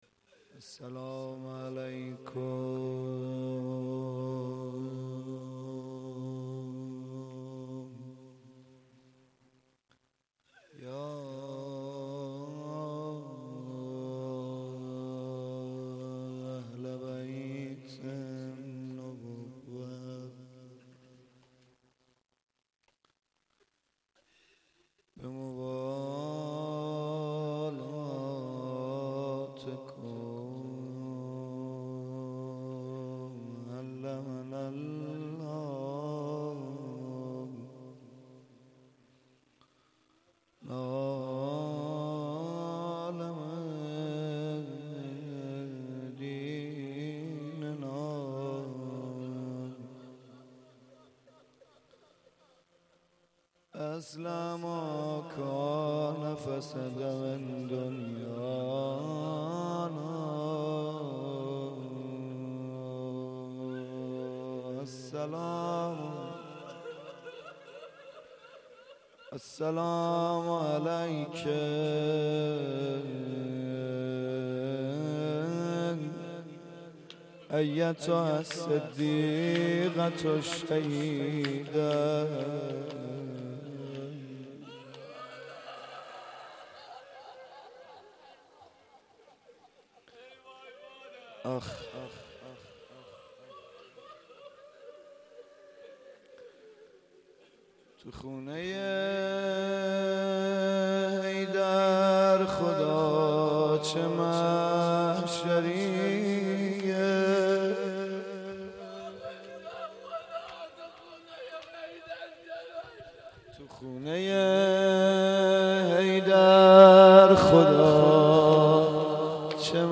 roze-fatemie92-shab3.mp3